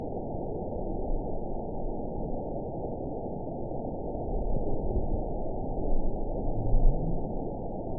event 922017 date 12/25/24 time 09:31:04 GMT (5 months, 3 weeks ago) score 8.67 location TSS-AB10 detected by nrw target species NRW annotations +NRW Spectrogram: Frequency (kHz) vs. Time (s) audio not available .wav